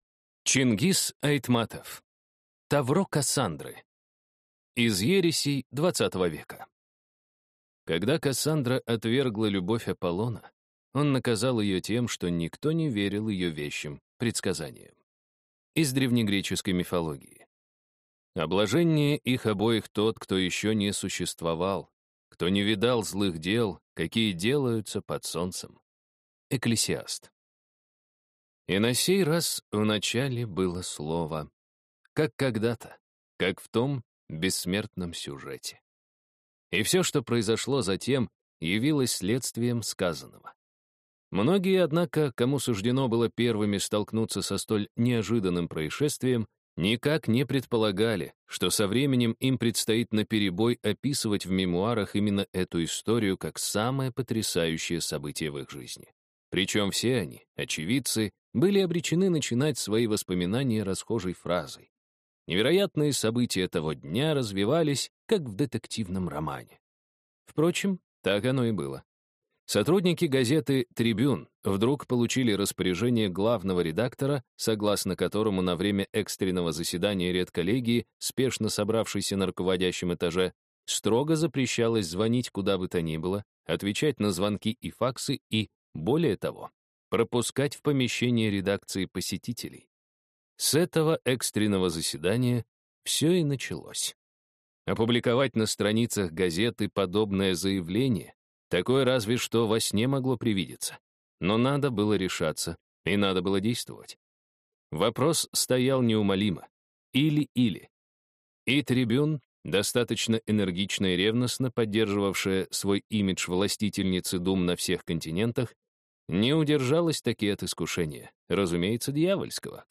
Аудиокнига Тавро Кассандры | Библиотека аудиокниг